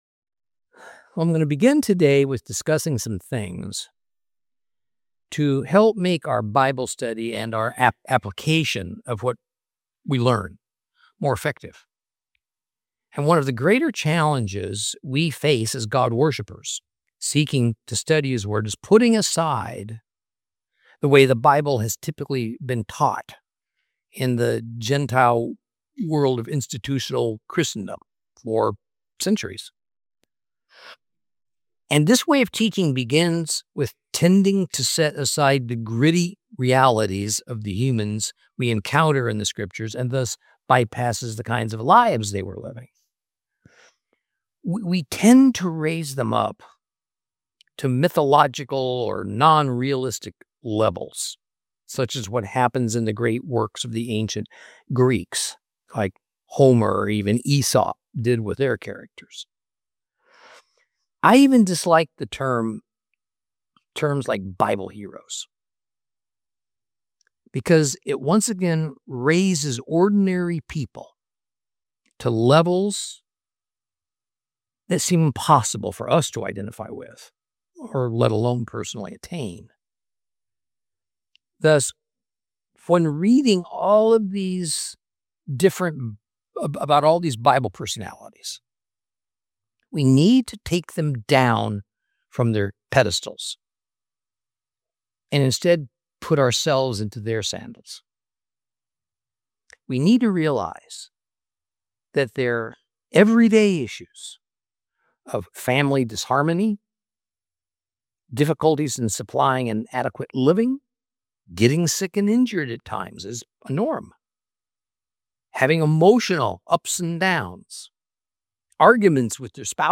Teaching from the book of Micah, Lesson 11 Chapter 6.